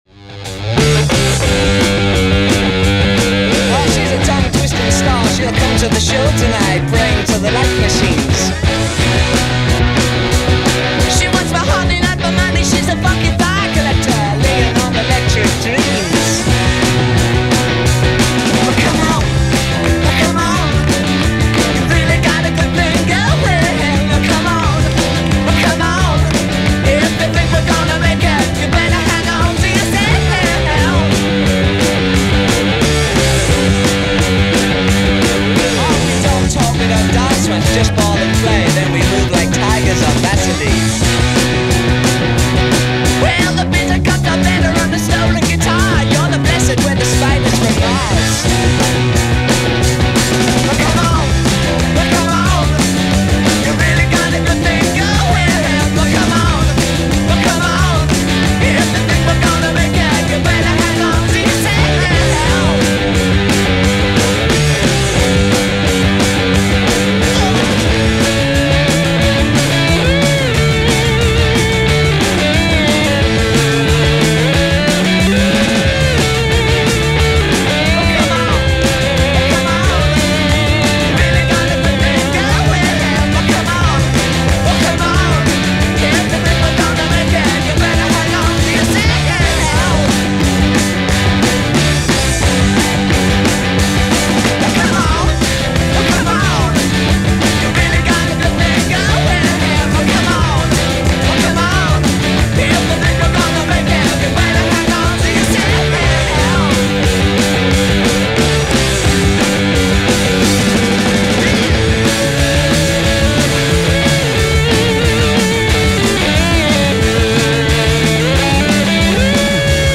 The dawn of Glam tonight.